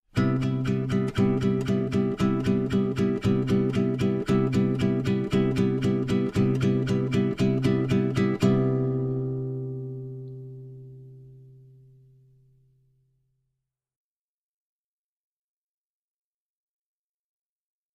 • Instrumentation : Guitare
• Genre : Jazz et Swing
• Style : Latin